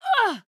音效